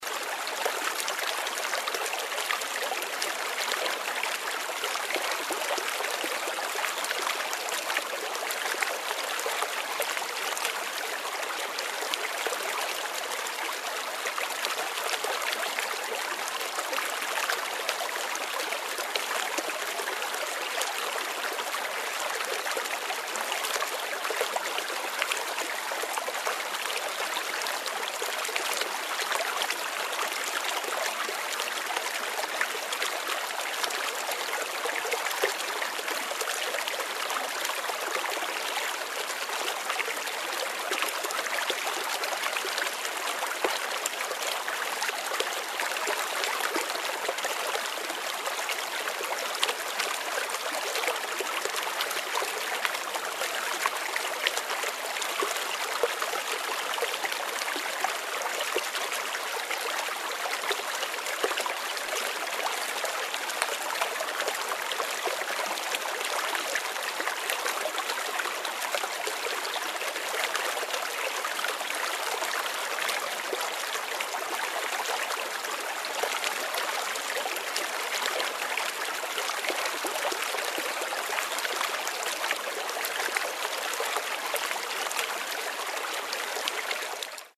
Звуки журчащего ручья
Погрузитесь в атмосферу природы с подборкой звуков журчащего ручья.